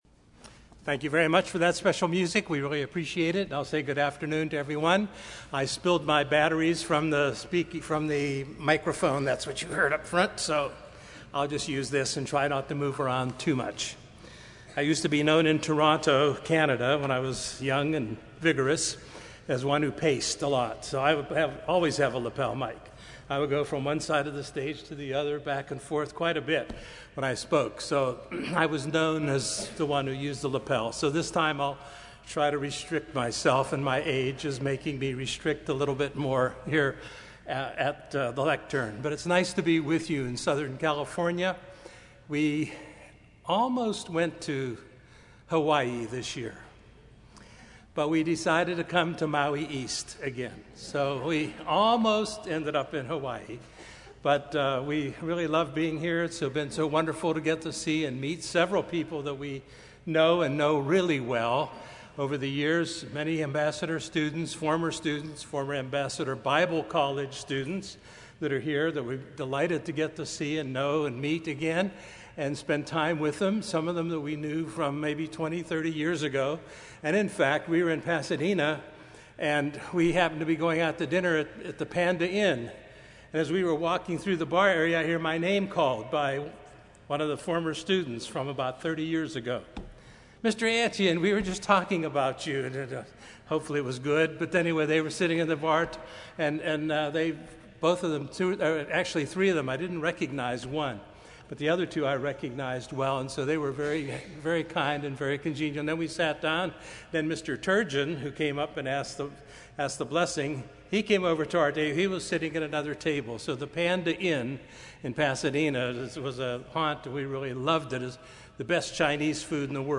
This sermon was given at the Oceanside, California 2015 Feast site.